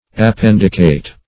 Appendicate \Ap*pend"i*cate\, v. t.
appendicate.mp3